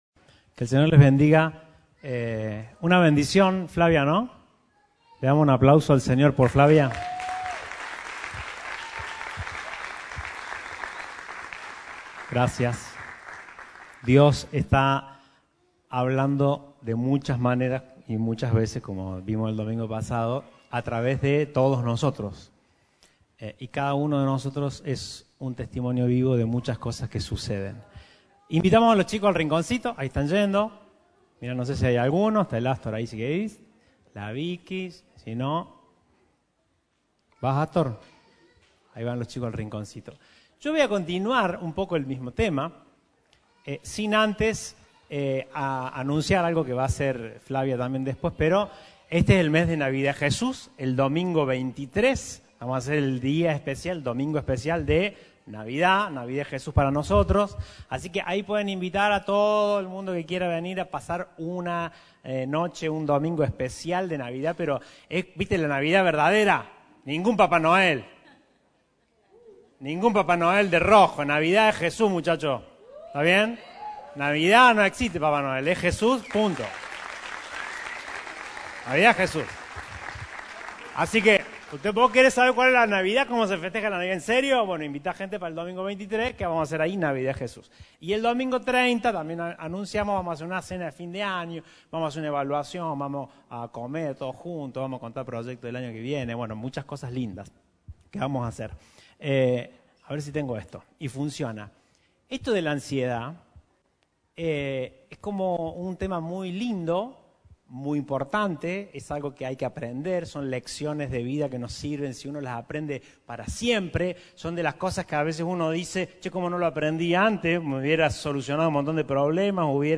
Compartimos el mensaje de este domingo 09/12/18